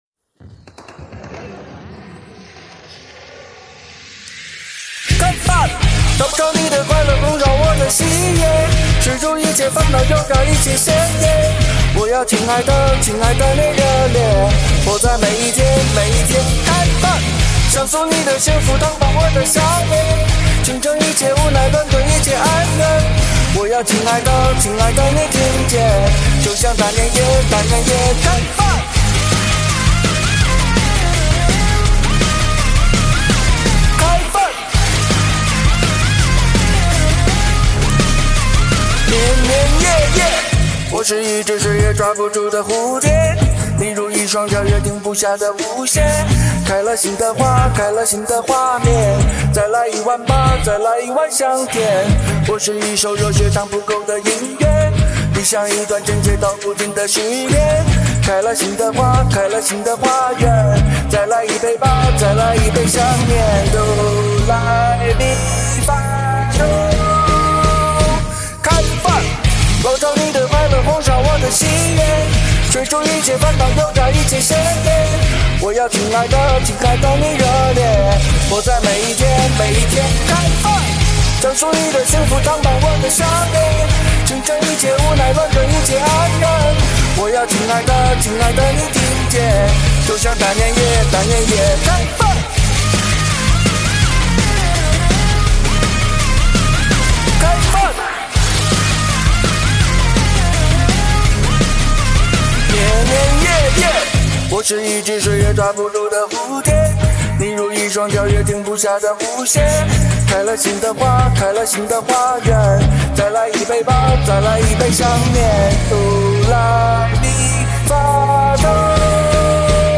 非常欢快的歌曲，听得人想跳起来，这歌曲发在这个时候太好了。